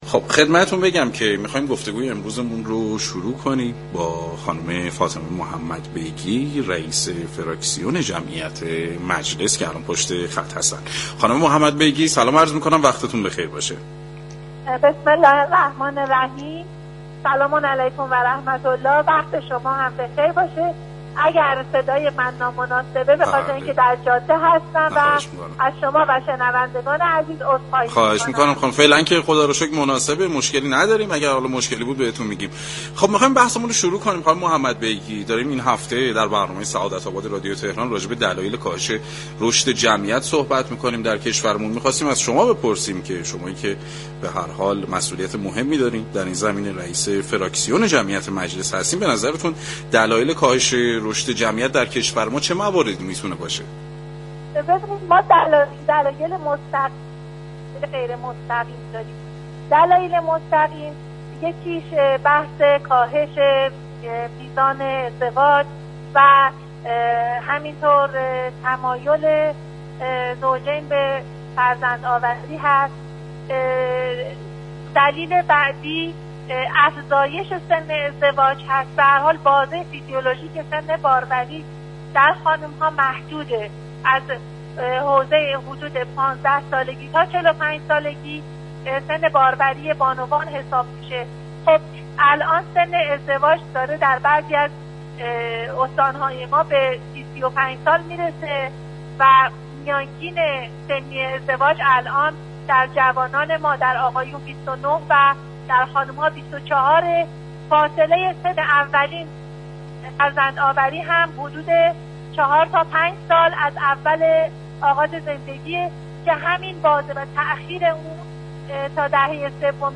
به گزارش پایگاه اطلاع رسانی رادیو تهران، فاطمه محمدبیگی نایب رئیس فراكسیون جمعیت و حمایت از خانواده مجلس یازدهم در گفتگو با برنامه سعادت آباد درباره دلایل رشد منفی جمعیت در كشور اظهار داشت: دلایل مستقیم و غیر مستقیمی برای كاهش نرخ رشد جمعیت وجود دارد.